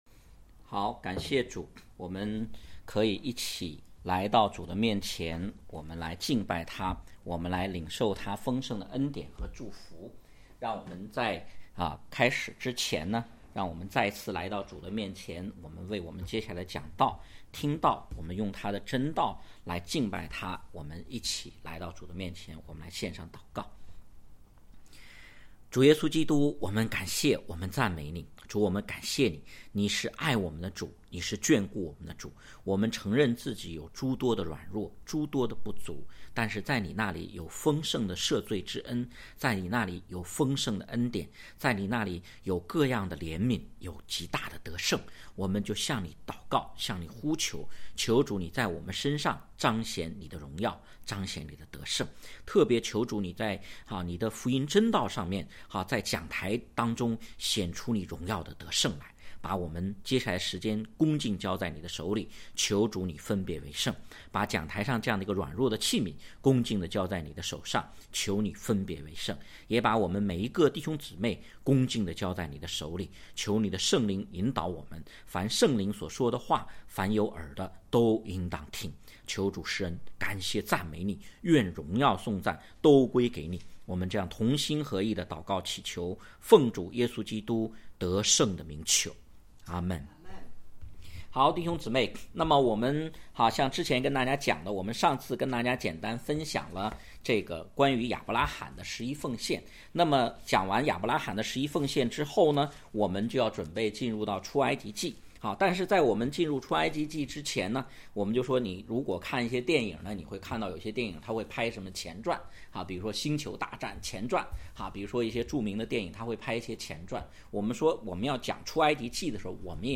讲道经文